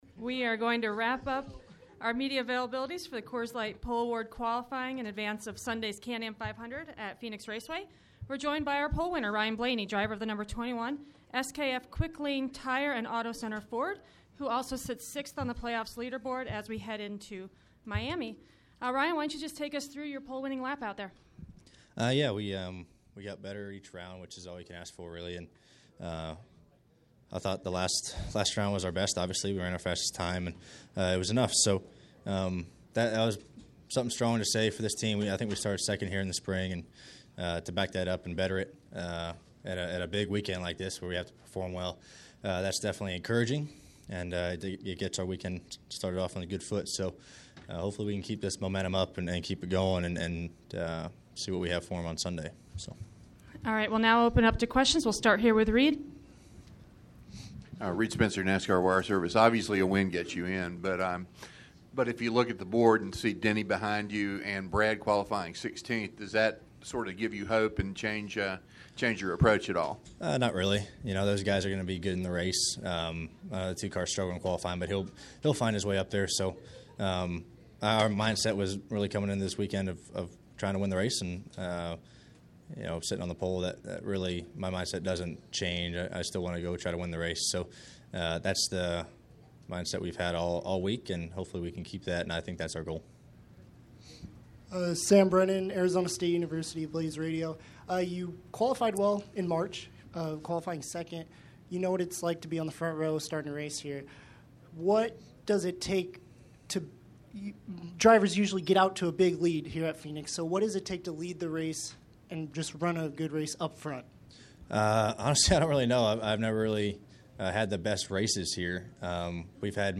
Media Center Interviews:
Pole winner Ryan Blaney –